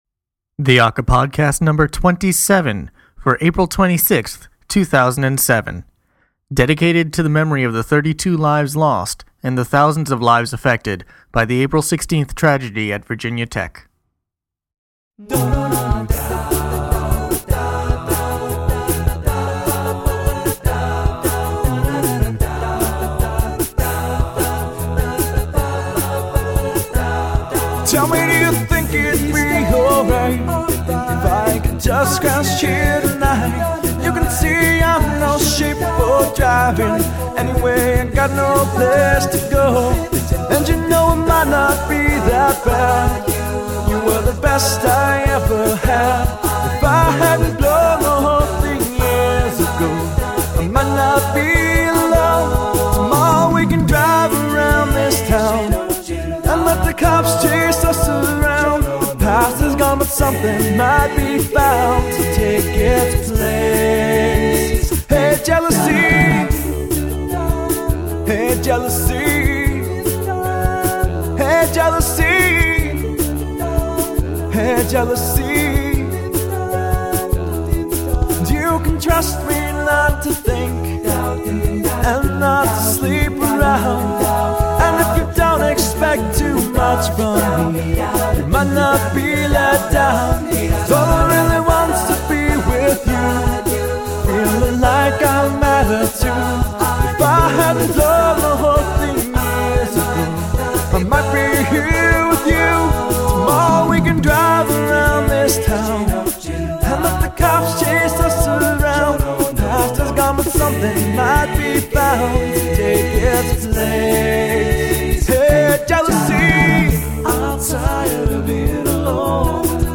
I talk a bit more in this episode than usual, but that’s because there are some serious topics to be brought up.